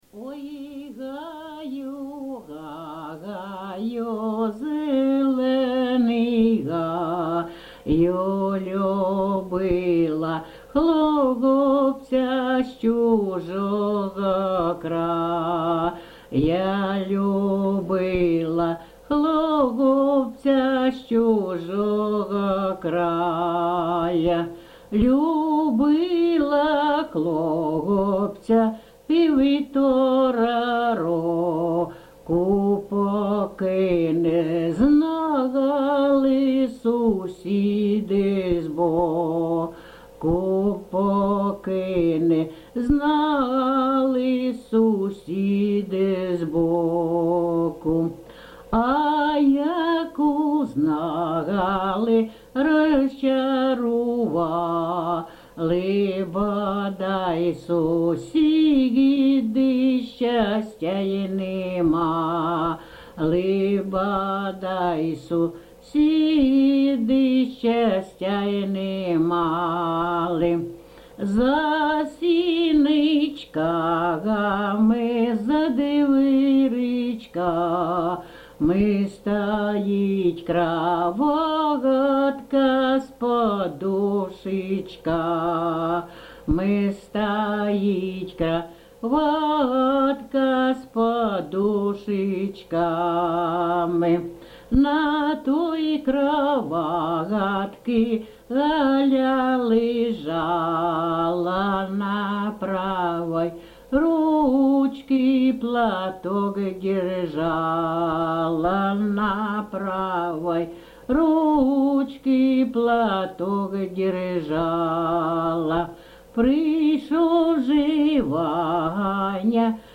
ЖанрПісні з особистого та родинного життя
Місце записус. Некременне, Олександрівський (Краматорський) район, Донецька обл., Україна, Слобожанщина